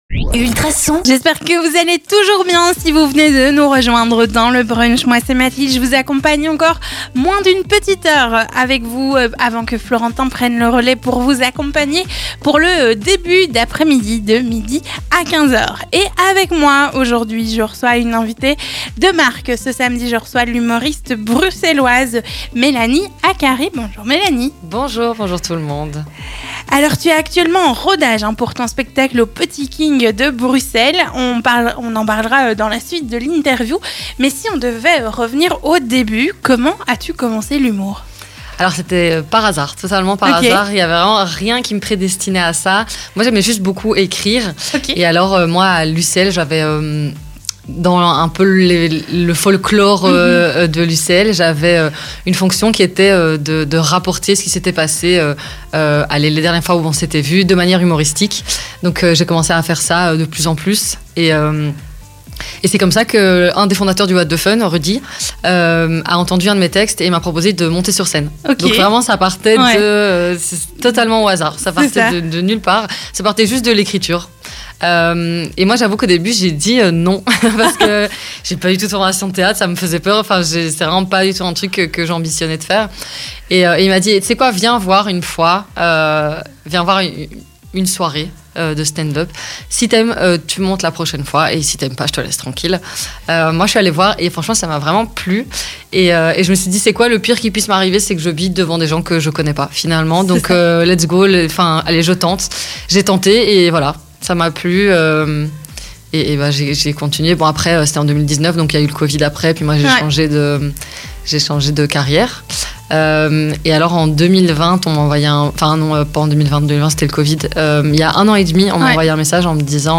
L'invité.e du Brunch